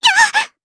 Rehartna-Vox_Damage_jp_02.wav